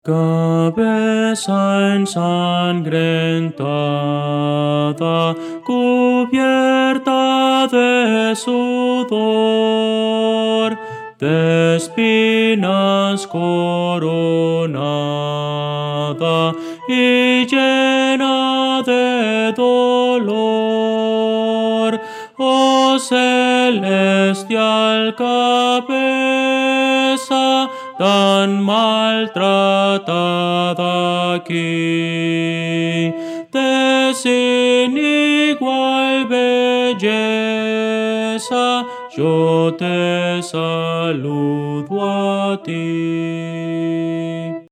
Voces para coro
Soprano
Audio: MIDI